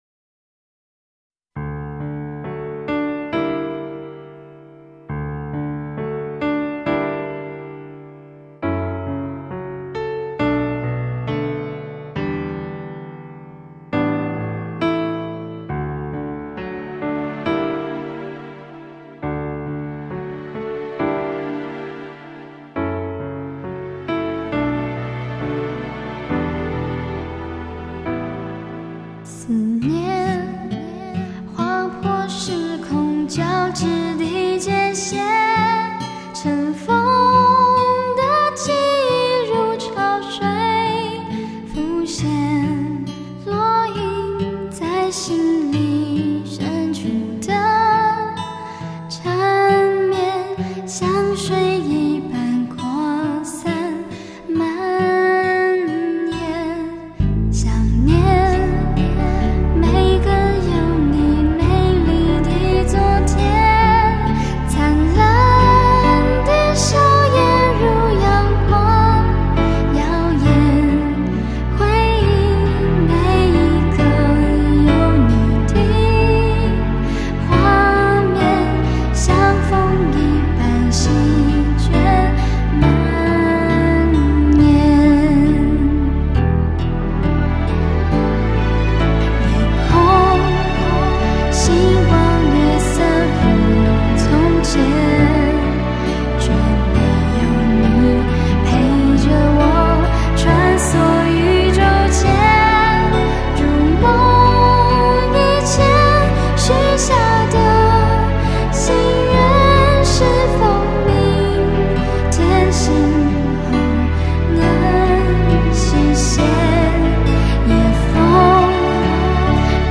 浪漫新世纪音乐
想像我的心跳，透过吉他传到你的耳边、想像我的思念，透过琴键凝结住你的视线；
想像我的爱情，透过小提琴在你的心上，画了一个又一个，温柔的圆圈；